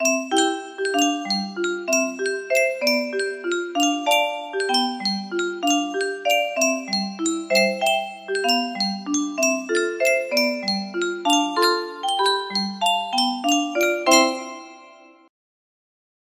Clone of Yunsheng Spieluhr - Kaiserwalzer 1293 music box melody